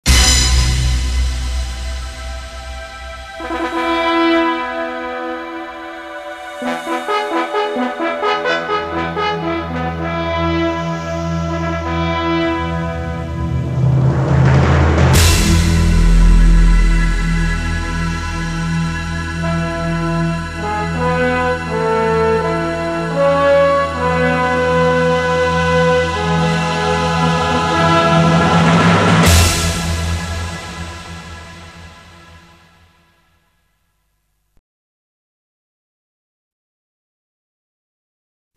Рыцарские фанфары, звучащие перед битвой